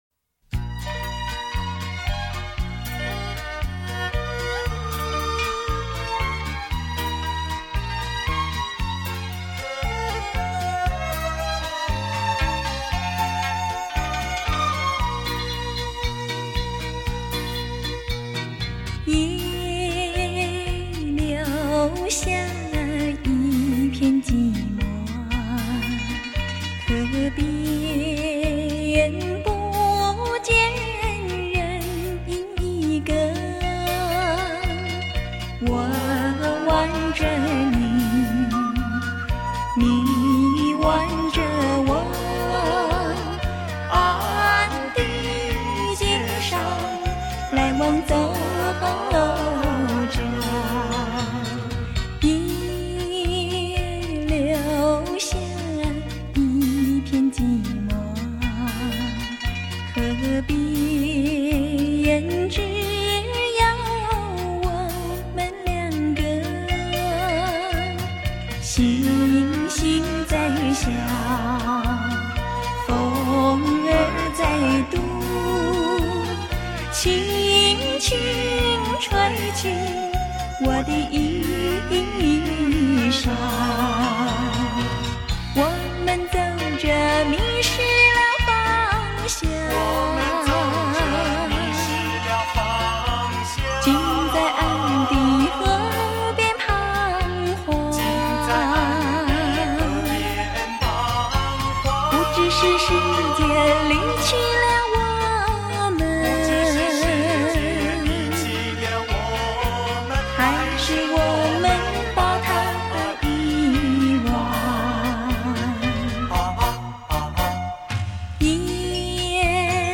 典雅温婉的歌声